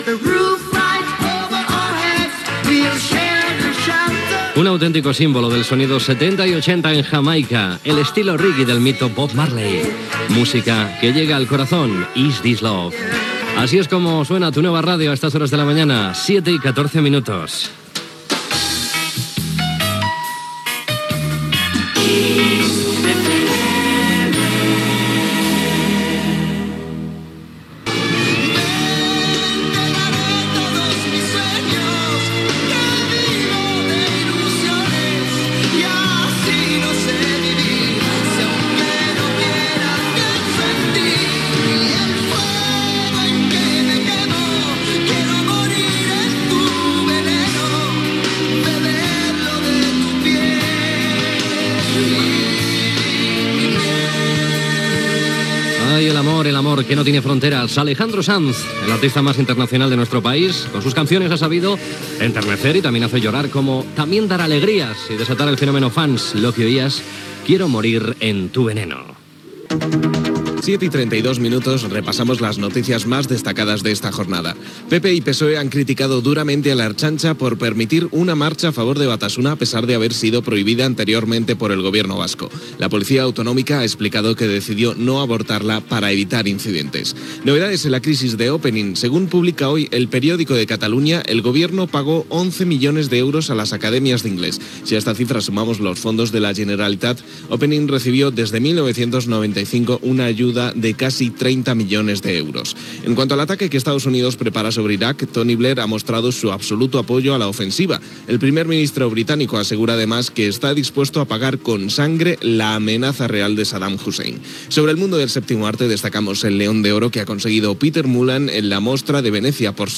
Tema musical, indicatiu de l'emissora, tema musical, hora, repàs a l'actualitat, tema musical
Primer dia d'emissió de Kiss FM a Barcelona.